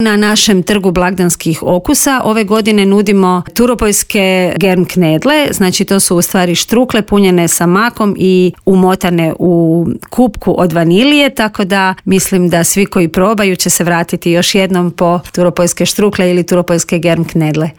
Advent u Gorici najavila je u Intervjuu Media servisa